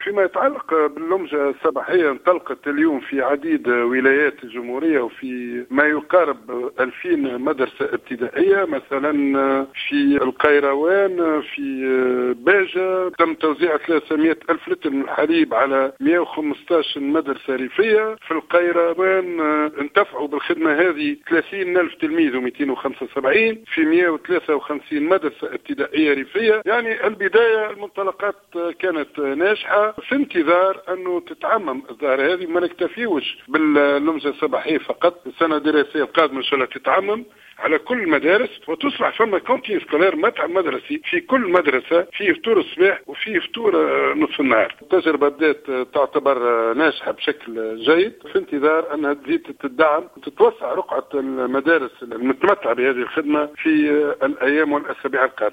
في اتصال هاتفي مع "الجوهرة أف أم"